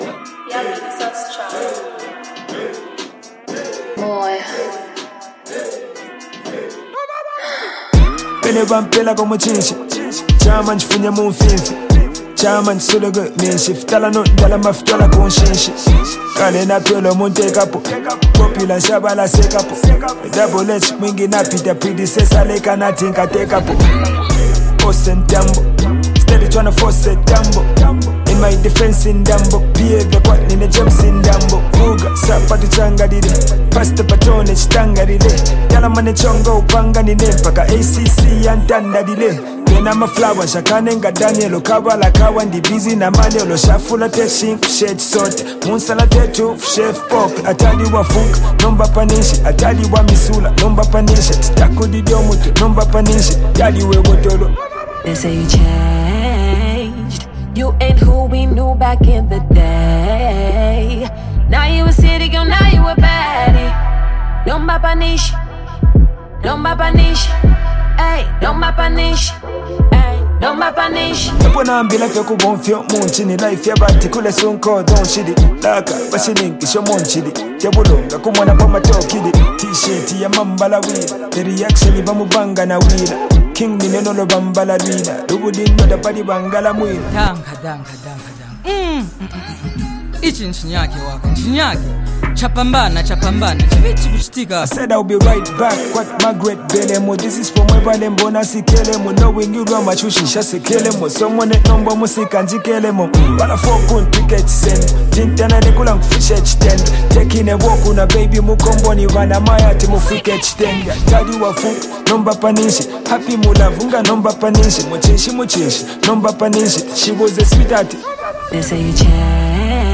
Zambian rapper